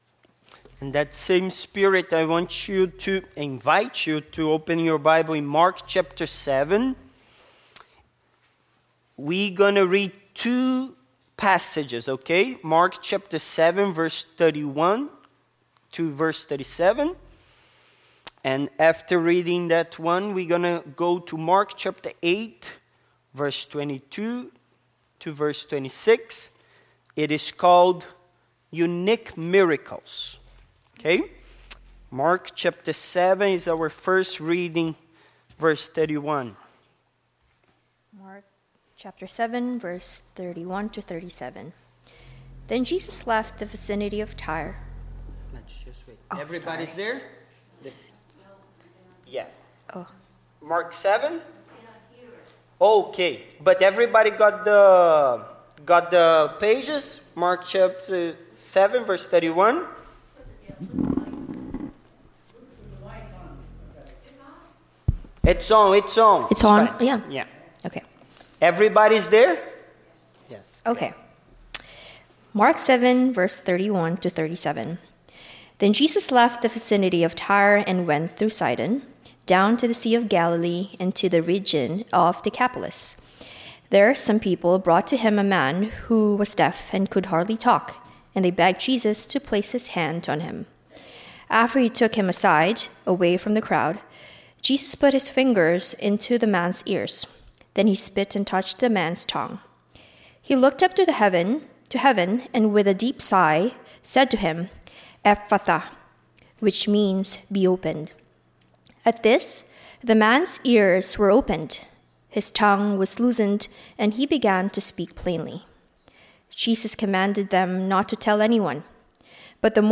Passage: Mark 7:31-37; Mark 8:22-26 Service Type: Sunday Morning